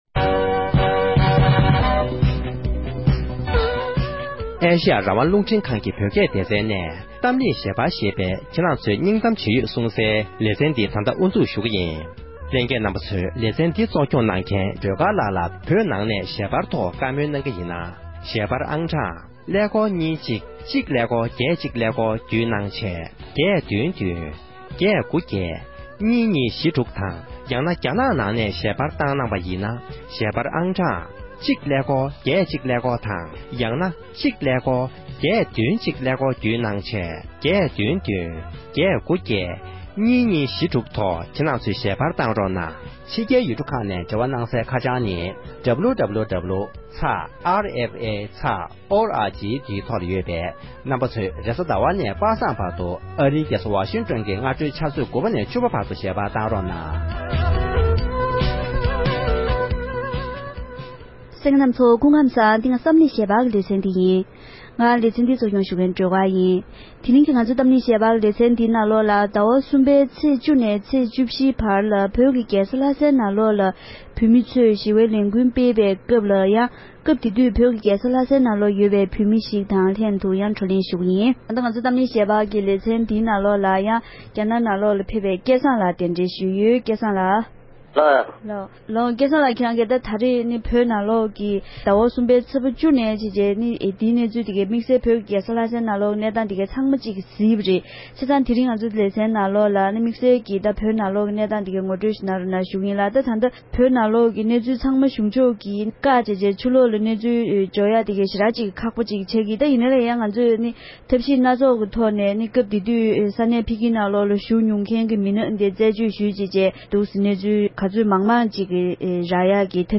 བོད་ཀྱི་རྒྱལ་ས་ལྷ་སའི་ནང་གི་ལས་འགུལ་ཁག་གཟིགས་མྱོང་མཁན་ཞིག་དང་གླེང་བ།